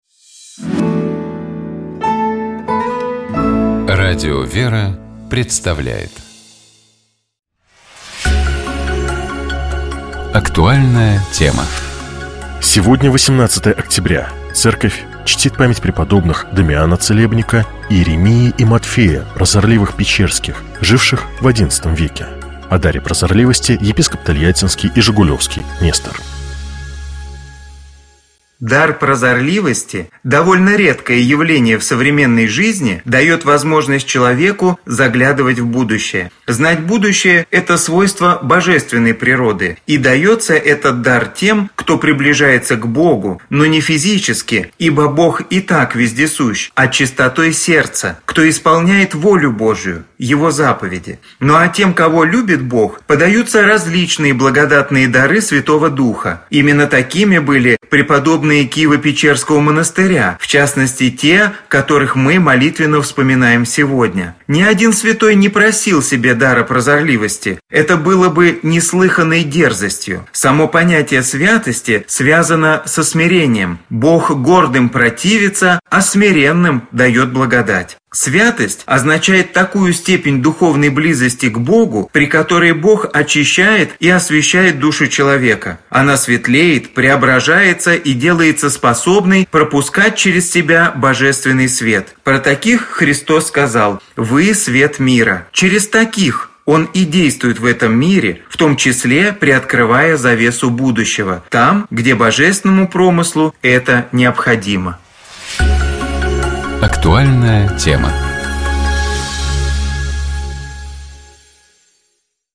О даре прозорливости, — епископ Тольяттинский и Жигулёвский Нестор.